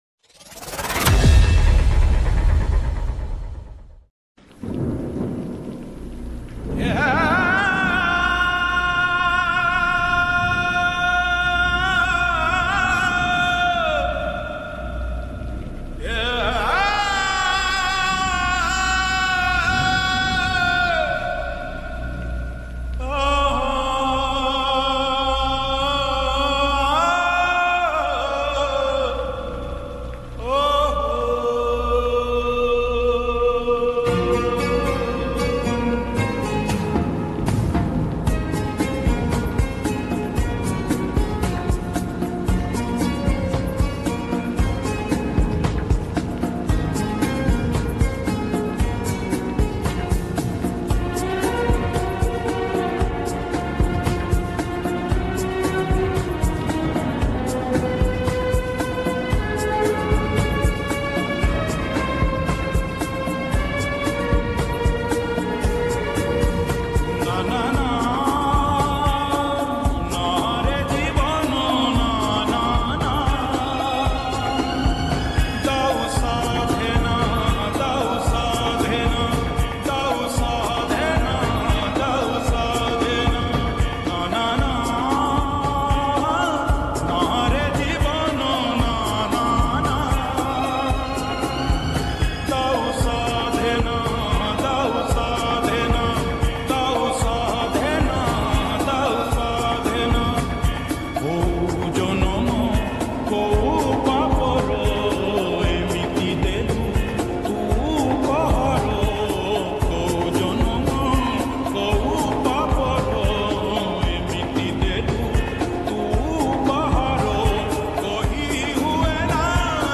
odia lofi song